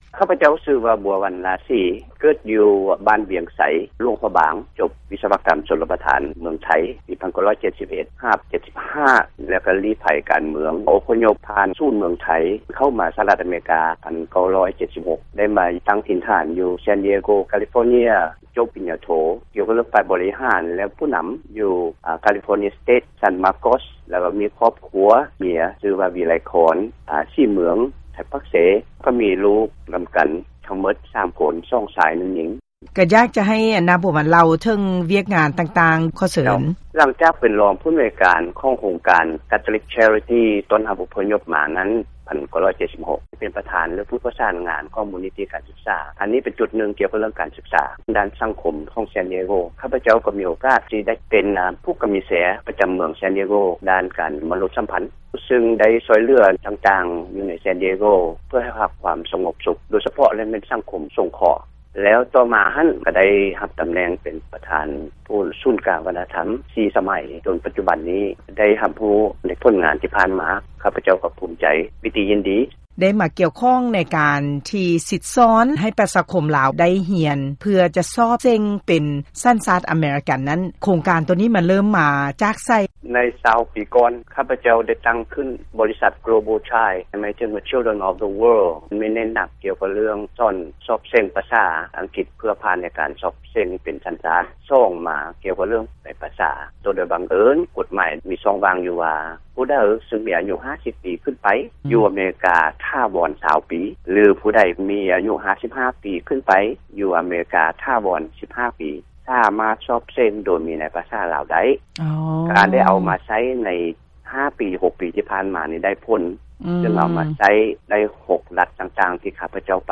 ການສຳພາດ ບຸກຄົນ ທີ່ກ່ຽວຂ້ອງ ໃນການປ່ຽນ ມາຖື ສັນຊາດ ອາເມຣິກັນ